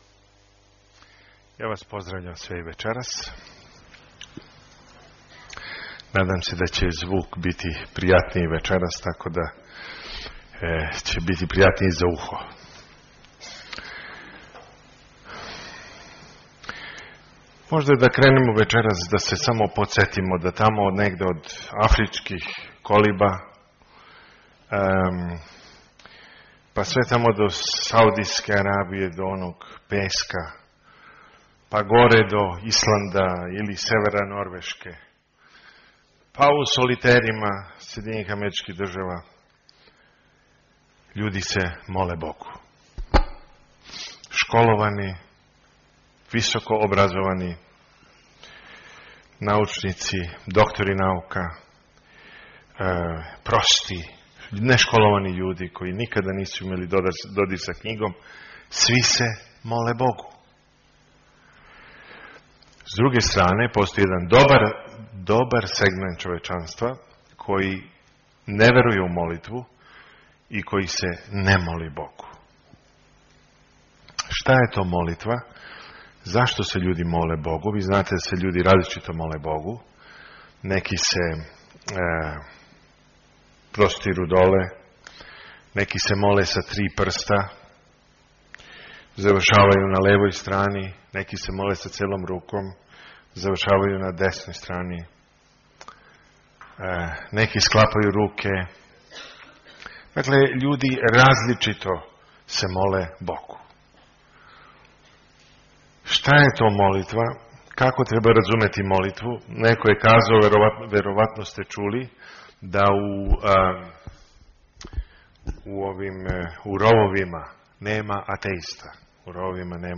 on 2010-01-31 - Predavanja 1 - 8 mp3